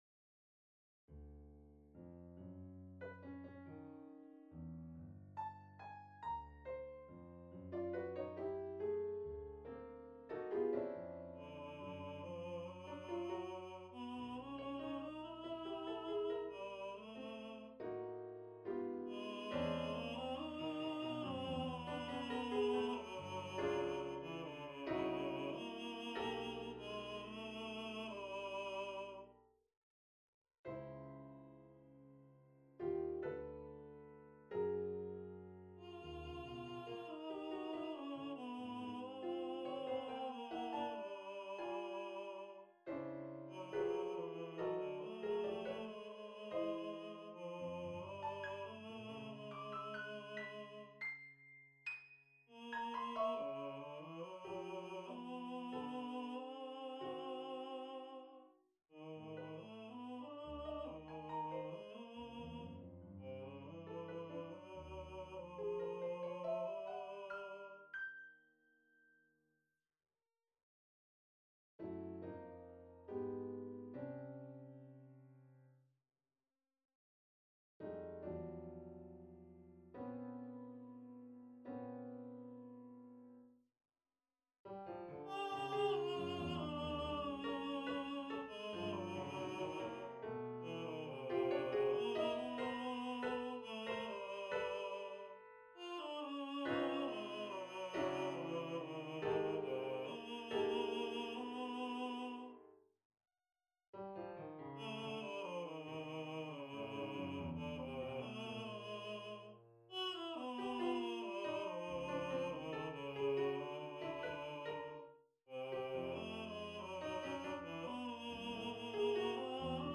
Calamus Blurts Four Whitman Songs on a purpose-selected tone row Op.37 1.
I saw in Louisiana - Andante Date Duration Download 2 February 2013 11'04" Realization (.MP3) Score (.PDF) 15.2 MB 192 KB